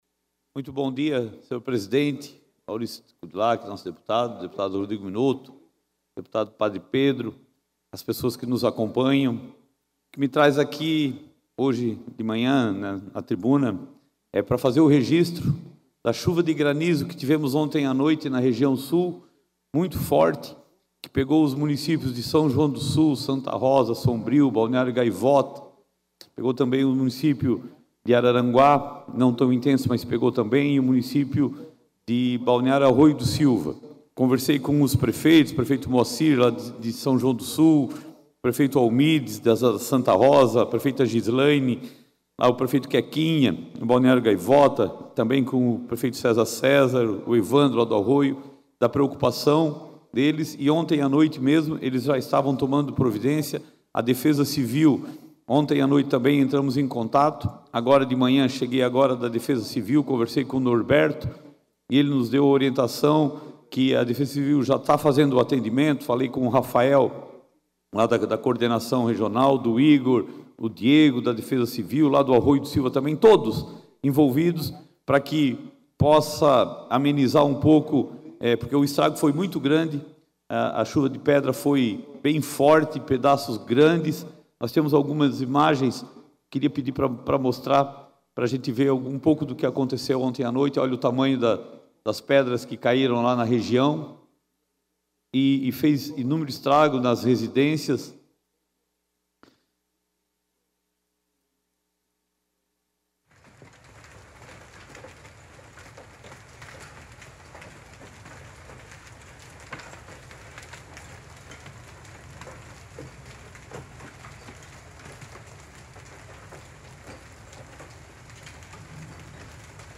Pronunciamentos da sessão ordinária desta quinta-feira (24)
- Tiago Zilli (MDB).